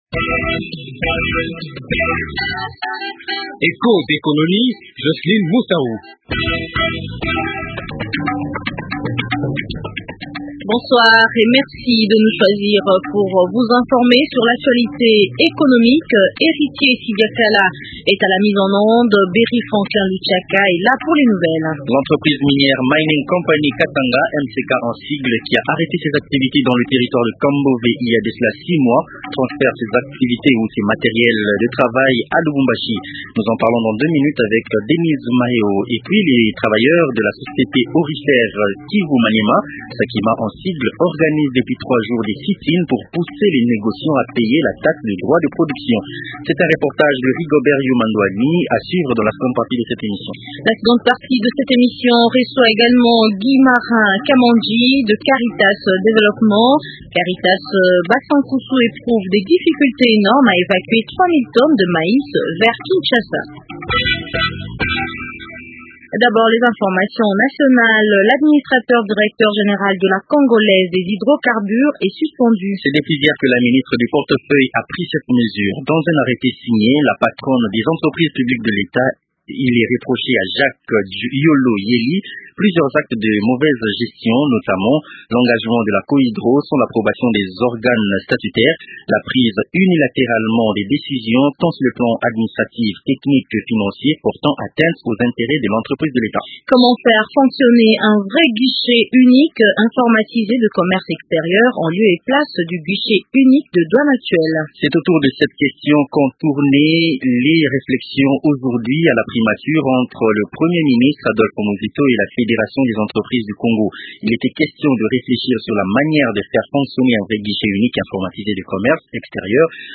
L’entreprise minière Mining compagny Katanga (MCK/ Kambove), après avoir arrêté ses activités dans ce territoire, a transféré ses matériels de travail à MCK/Lubumbashi. Les travailleurs de la Société aurifère Kivu Maniema (Sakima) organise, depuis trois jours, des sit-in pour pousser les négociants à payer la taxe de droit de production. Reportage à suivre dans Echos d’économie.